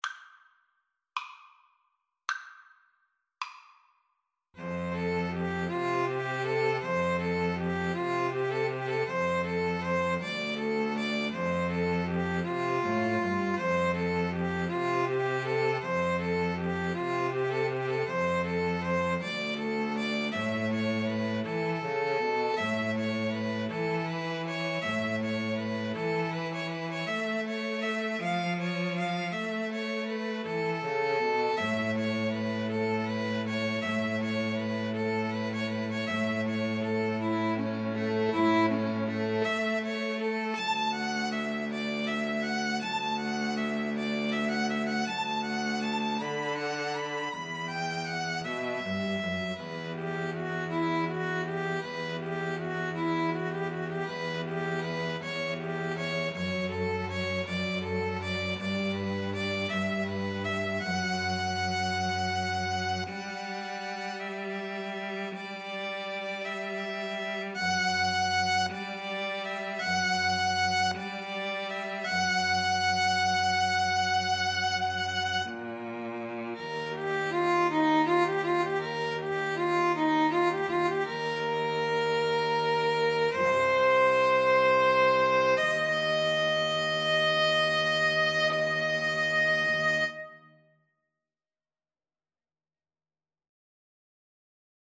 Free Sheet music for String trio
ViolinViolaCello
F major (Sounding Pitch) (View more F major Music for String trio )
Andante
6/8 (View more 6/8 Music)
Classical (View more Classical String trio Music)